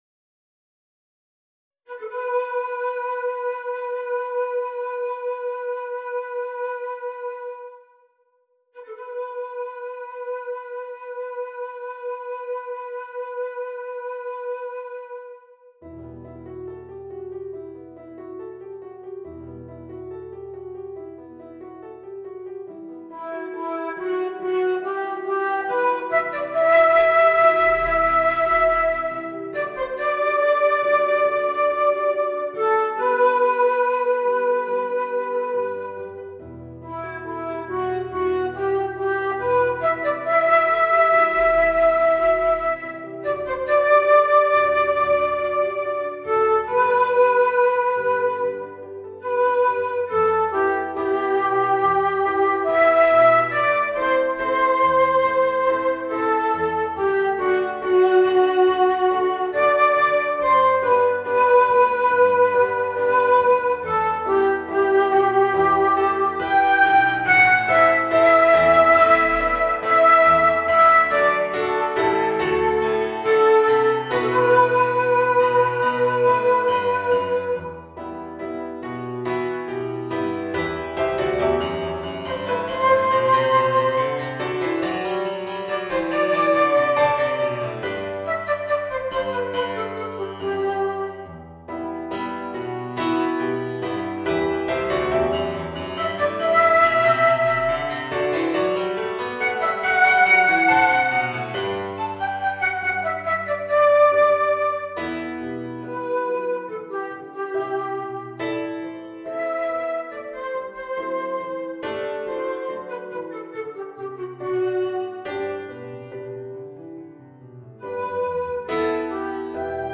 Panflöte & Klavier